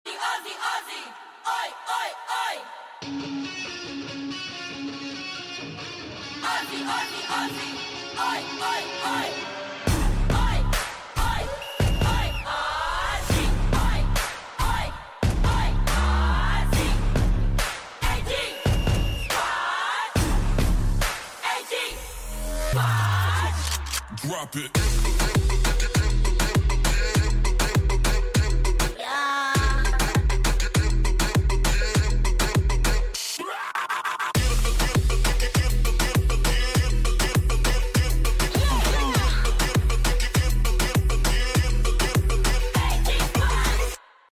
AG SQUAD CHEER SOUND/MOVING POSTER sound effects free download